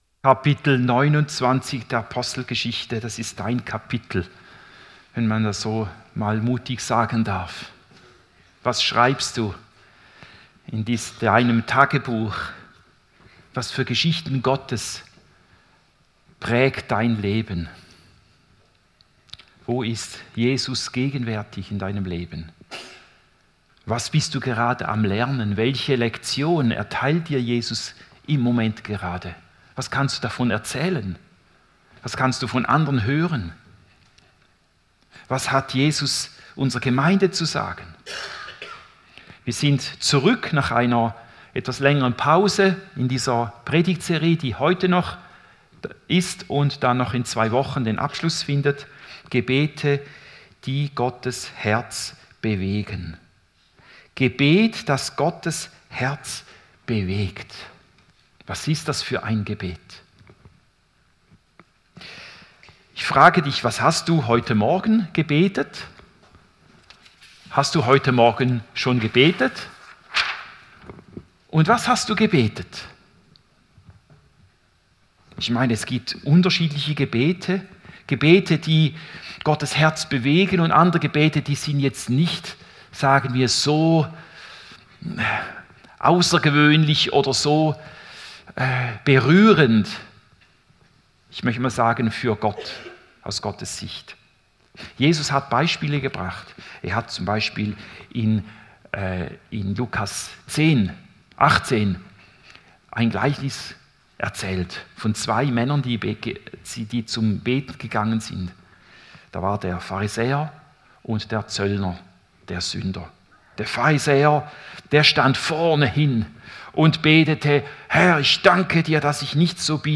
Predigt 12. April 2026